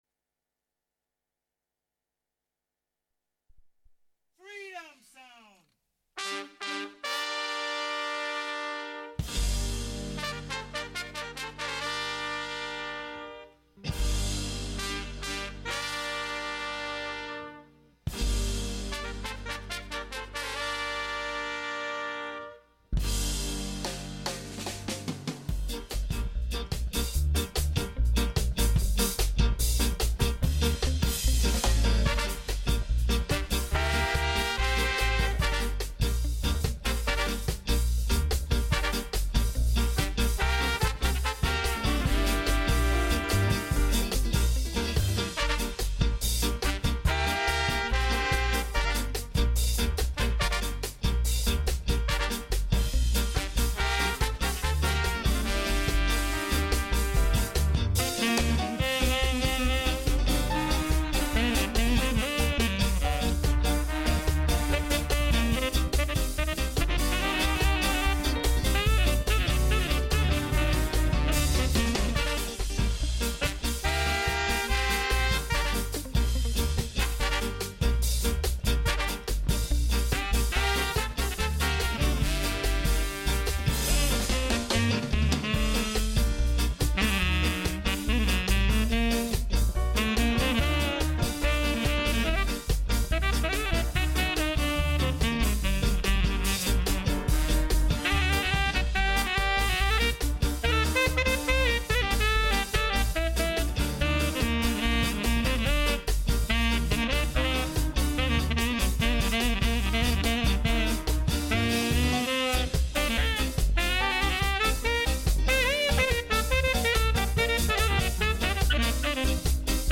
Reggae Radio Show